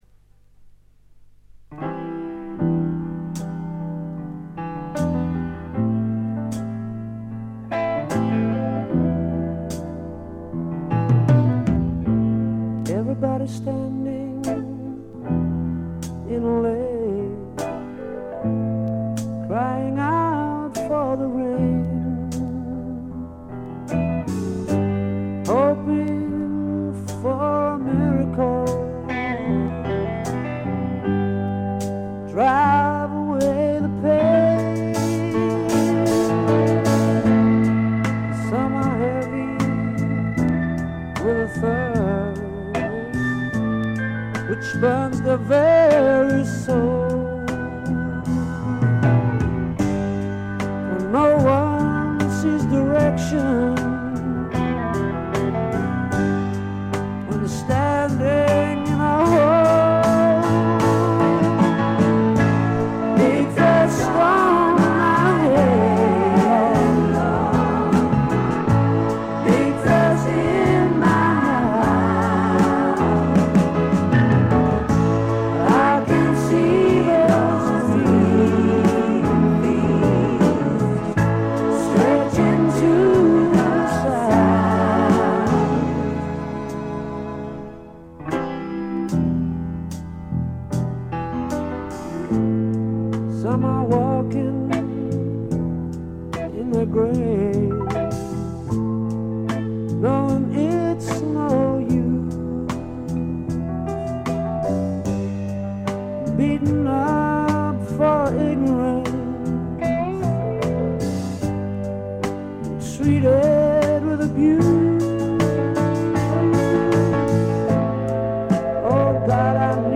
静音部での微細なバックグラウンドノイズ程度。
マッスルショールズ録音の英国スワンプ大名盤です！
試聴曲は現品からの取り込み音源です。